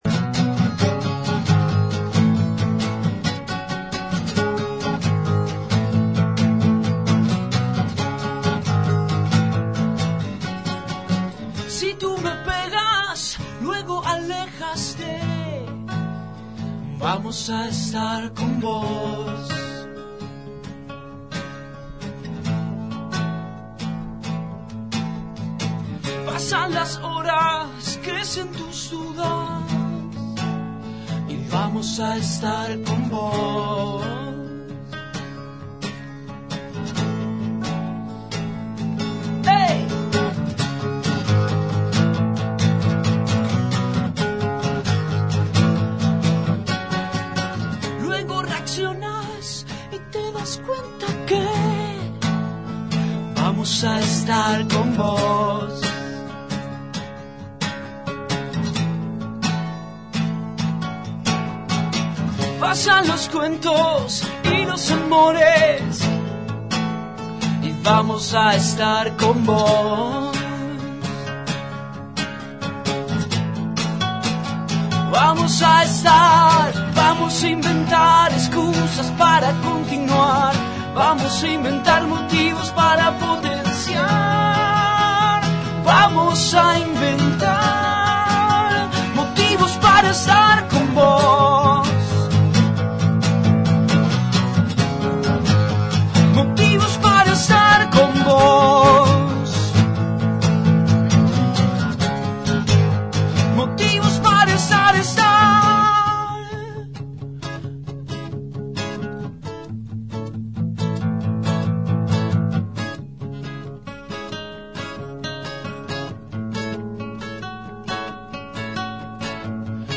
En el día de la música, pasaba por el programa Abramos La Boca en la Columna de Cultura; la Banda ChinelasPersas.